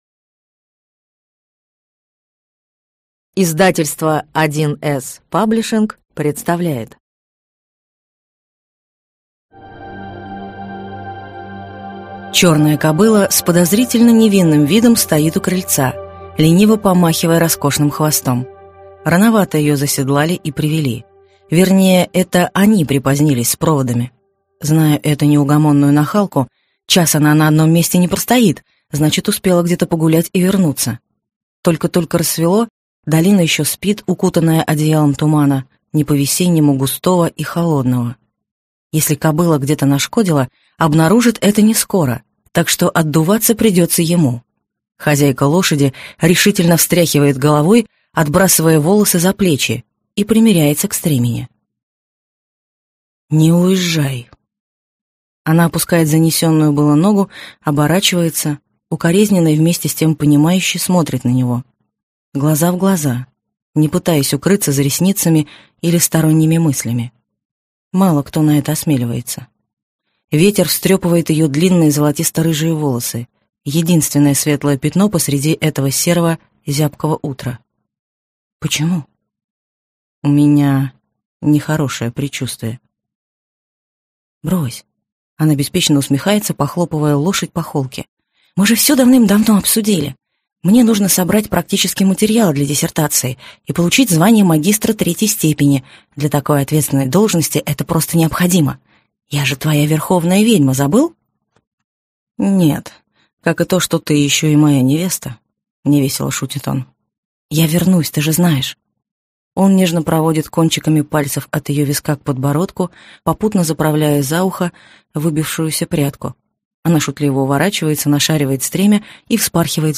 Аудиокнига Верховная Ведьма - купить, скачать и слушать онлайн | КнигоПоиск